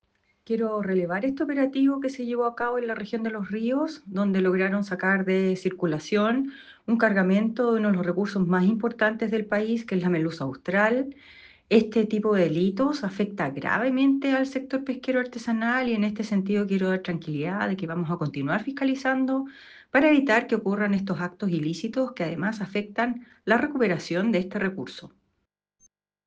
SoledadTapia_DirectoraNacional.ogg